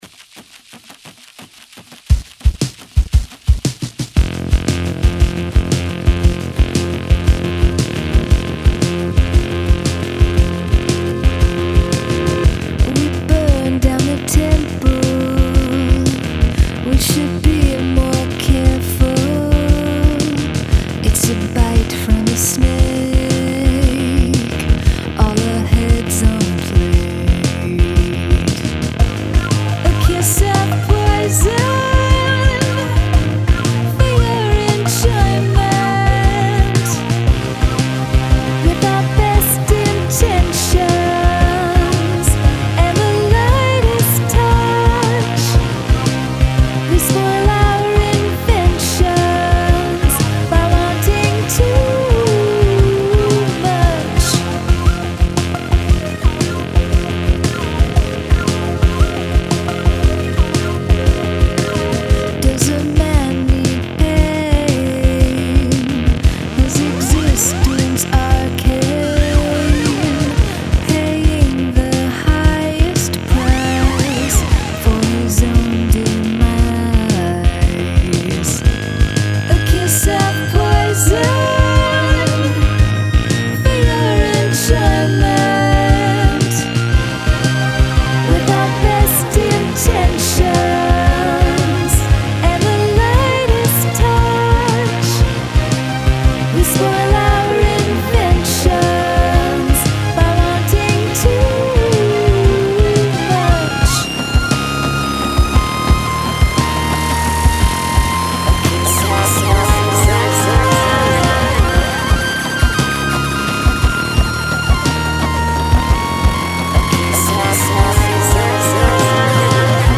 Have a guest play a household item on the track
stompy glam-rock drums! Incredibly chunky bassline!
The tupperware sounds like it absolutely belongs.